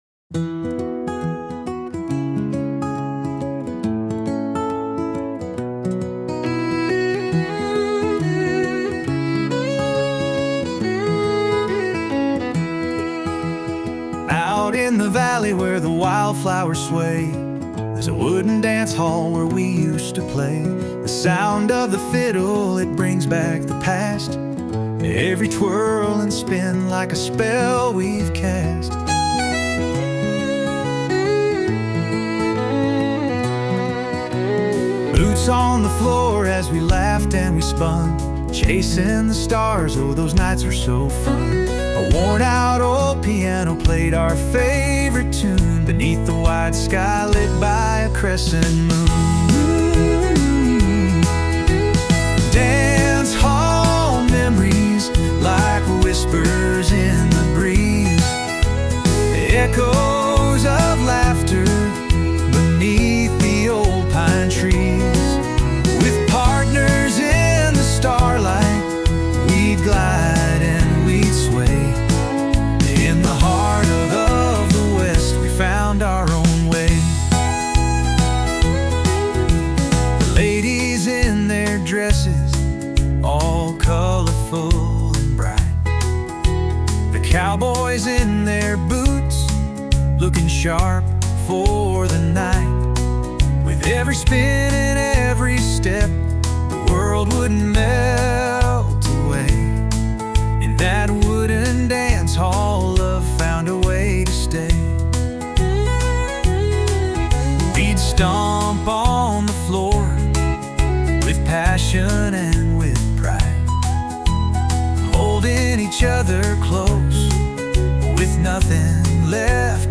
Experience the joyful melodies